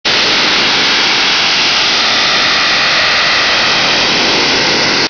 Réception DRM : Digital Radio Mondiale
DRM.wav